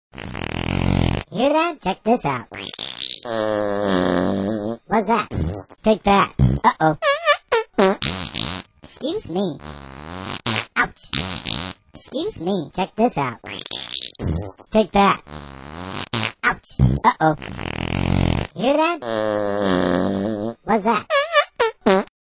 Windy..... The Babble Balls that FARTS!
Featuring an exquisite collection of diverse, high quality designer farts and associated commentary. She�s got the thunder!